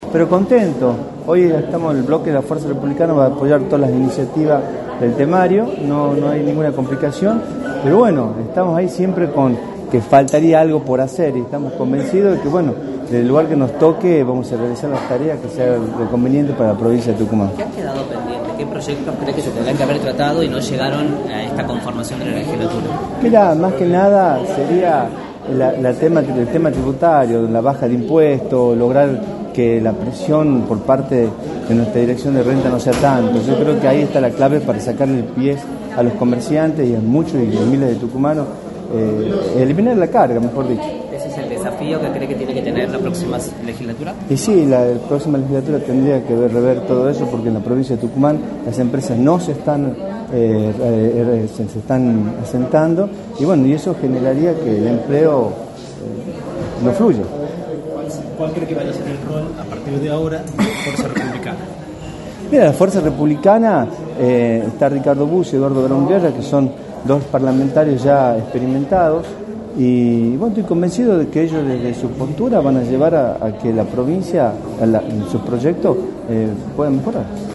Gerardo Huesen, dirigente de Fuerza Republicana, analizó en Radio del Plata Tucumán, por la 93.9, cómo fue su gestión en la Legislatura y cuáles son los proyectos que quedaron pendientes.